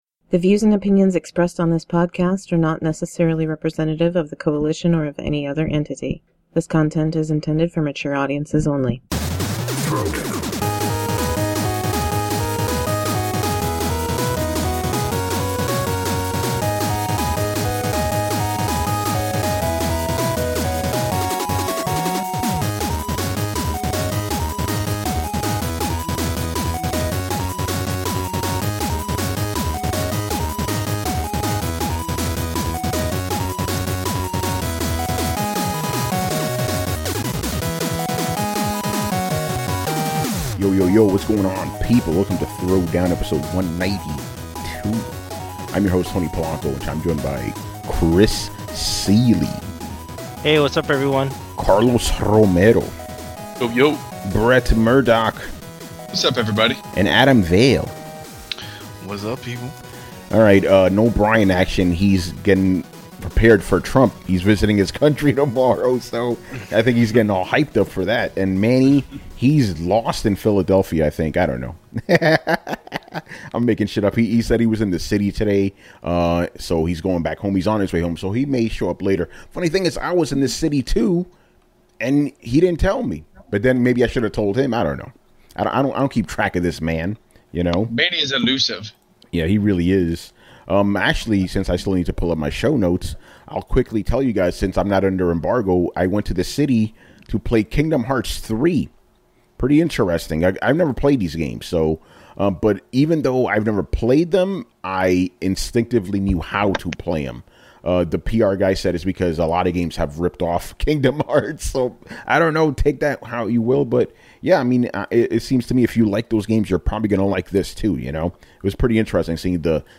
On this podcast/vidcast we discuss the latest video game news and topics in an uncompromising and honest manner.